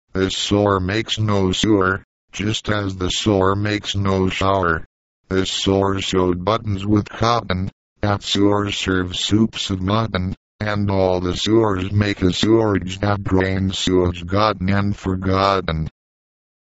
唸音